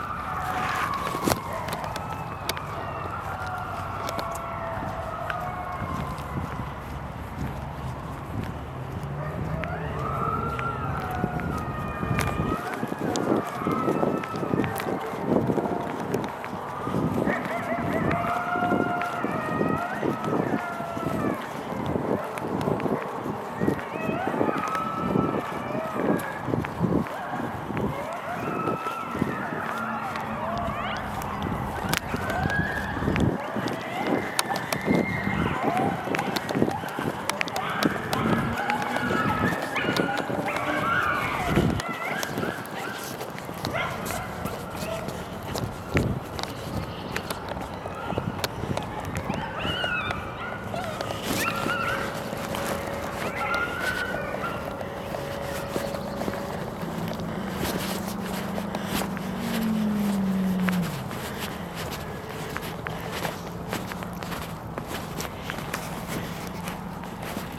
I tried catching up but didn’t. They began their trot after howling first in response to each other — probably locating one another — and then continuing their howling in response to sirens (listen below).
The footprints would soon also dissipate and disappear, but they remained for now, long enough for me to record them with my camera, even though the coyotes themselves were long gone — like a soft whisper, and unlike the cacophony that began this observation (apologies for the sound of my footsteps in the recording):
Mated pair communicate at dawn. This is only TWO coyotes!